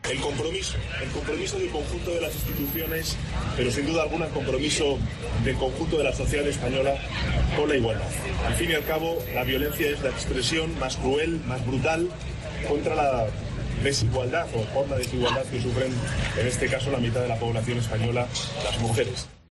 El presidente del Gobierno en funciones, Pedro Sánchez, ha expresado este domingo el compromiso de las instituciones y el conjunto de la sociedad con la igualdad y la lucha contra la violencia de género, que ha definido como "la expresión más cruel y más brutal contra la desigualdad".
Sánchez se ha sumado a la sexta Carrera contra la violencia de género, que ha recorrido los cinco kilómetros del perímetro del parque madrileño del Retiro y cuyos beneficios se destinarán a las víctimas.